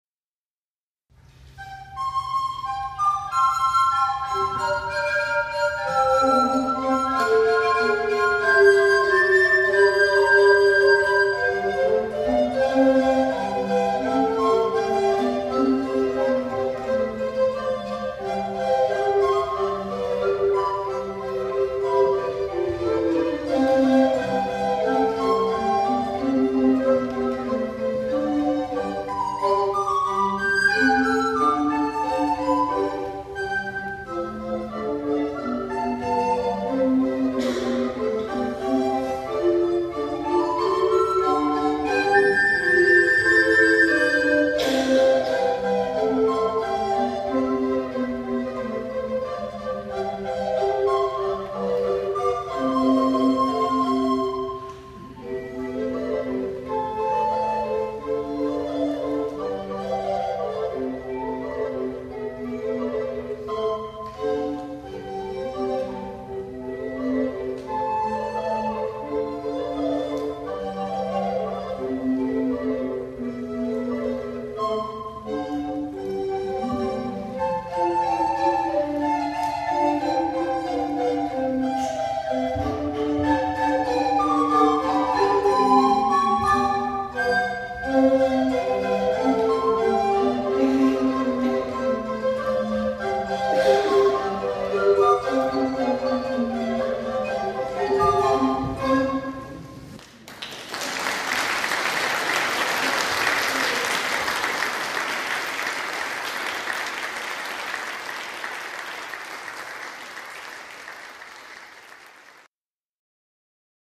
Das Flötenensemble
Mit Schwung und Elan musiziert unser Flötenensemble.
Hier können Sie das Flötenensemble mit einem Stück aus einem der Jahreskonzerte hören. "At Circus" aus dem Konzert 2012